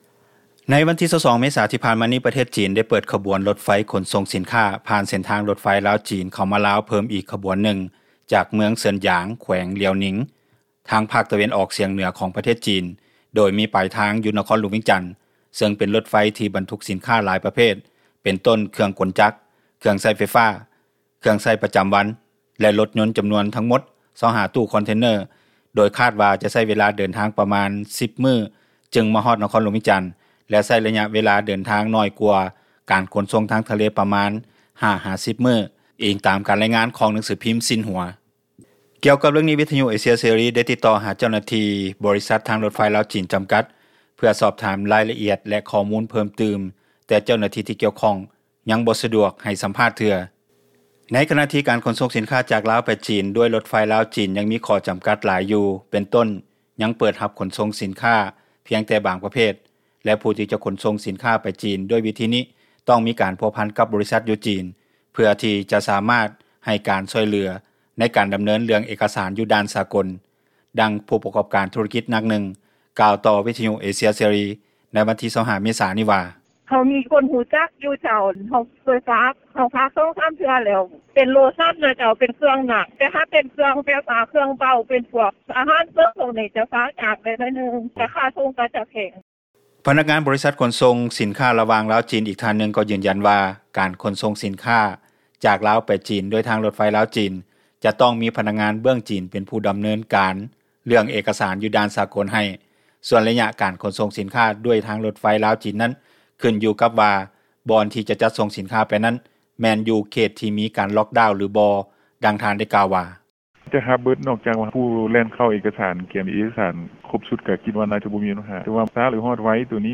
ດັ່ງຜູ້ປະກອບການທຸຣະກິຈ ນາງນຶ່ງກ່າວຕໍ່ວິທຍຸເອເຊັຽເສຣີ ໃນວັນທີ 25 ເມສານີ້ວ່າ:
ດັ່ງພະນັກງານ ບໍຣິສັດຮັບຂົນສົ່ງສິນຄ້າ ຈາກຈີນມາລາວກ່າວວ່າ: